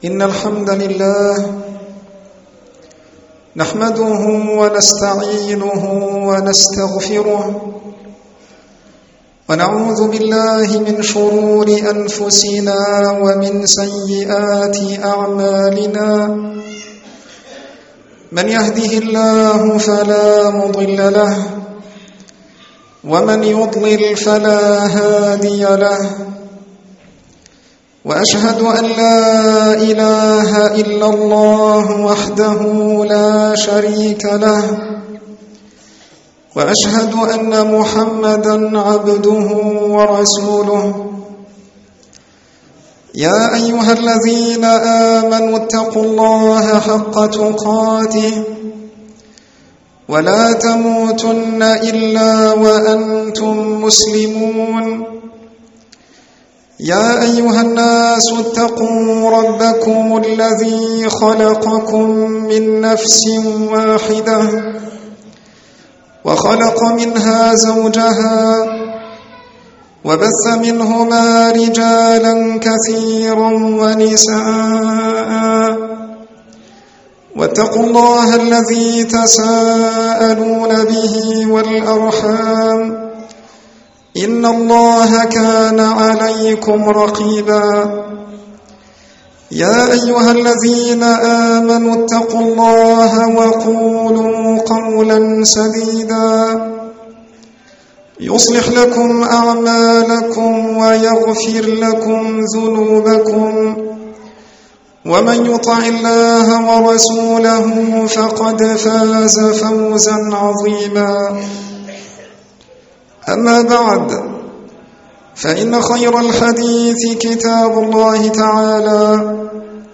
الخـطب